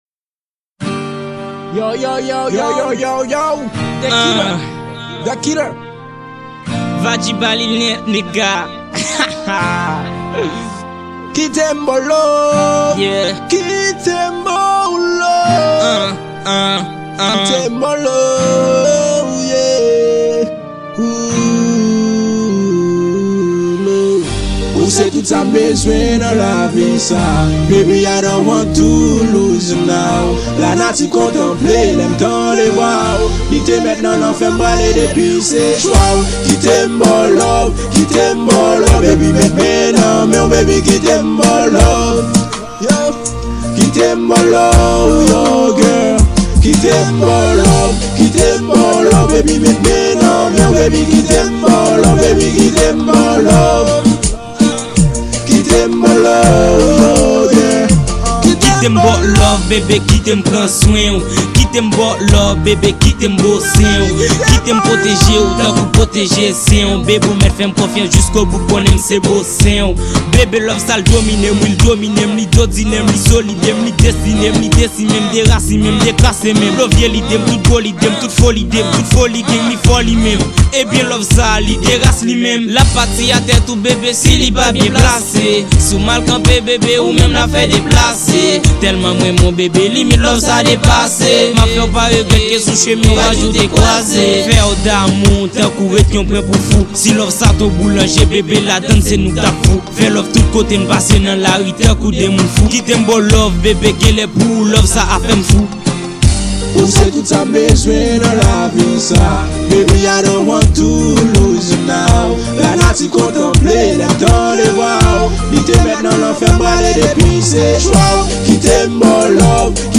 Genre: RAP/RNB.